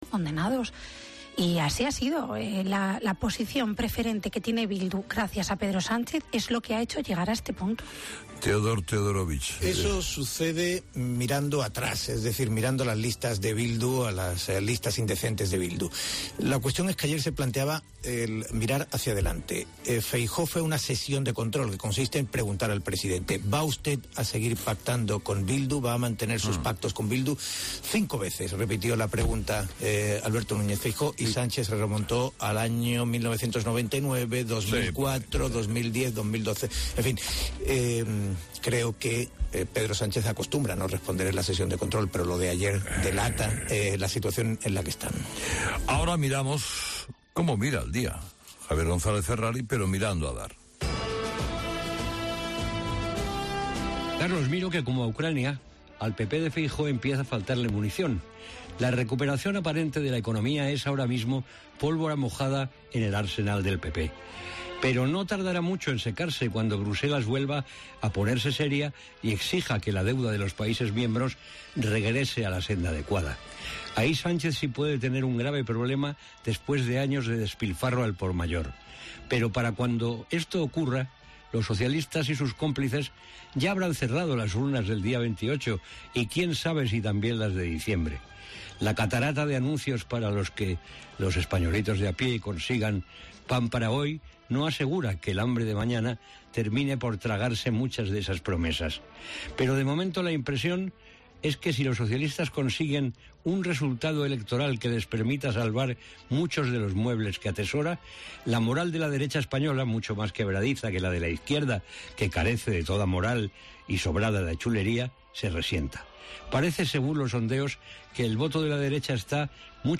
Informativo matinal Herrera en Cope 17 de Mayo